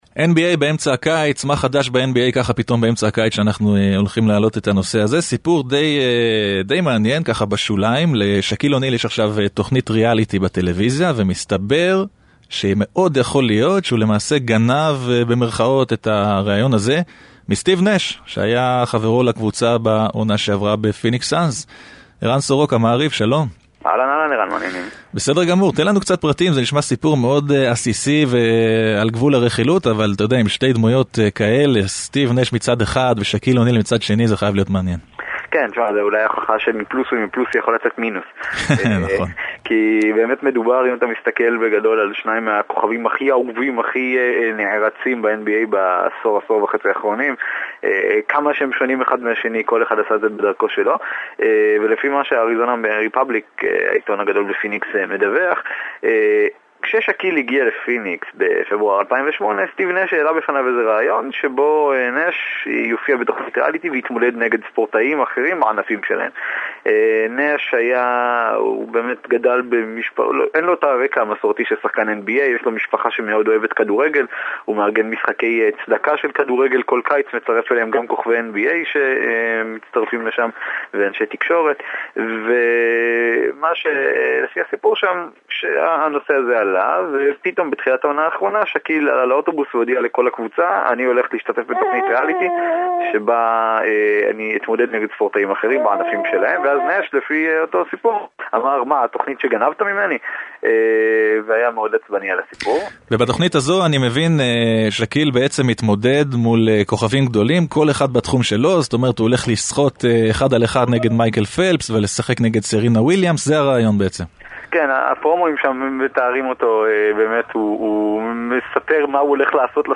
שלושה ראיונות כדורסל מתוך תוכנית הספורט של רדיו 99.